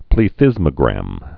(plē-thĭzmə-grăm, plə-)